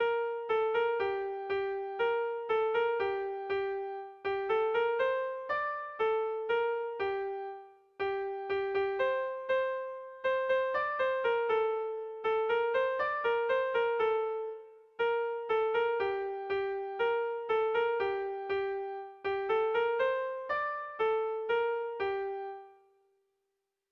Sentimenduzkoa
Seiko handia (hg) / Hiru puntuko handia (ip)
ABA